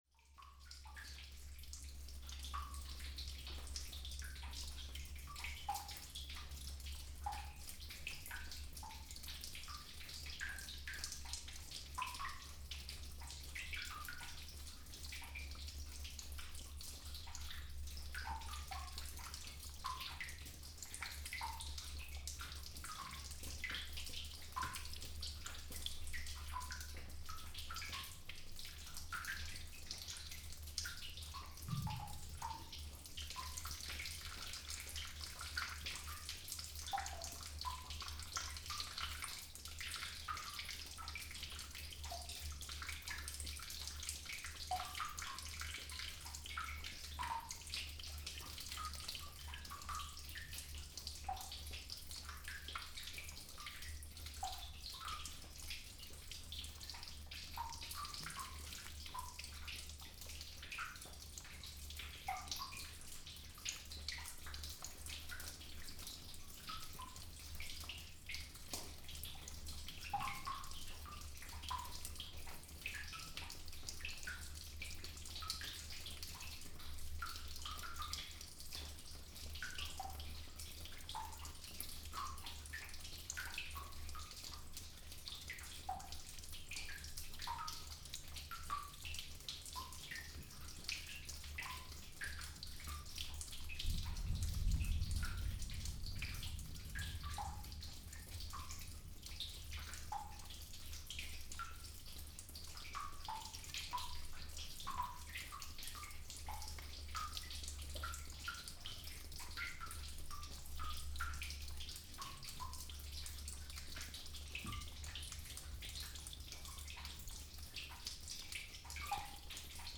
I was missing out on unique opportunities if I would not change my traditional methods of recording glacier sounds. Glaciers that have so far been accessible with all kind of sound seems much quieter than before.
mp1_006-svinafellsjokull.mp3